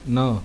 33_na.mp3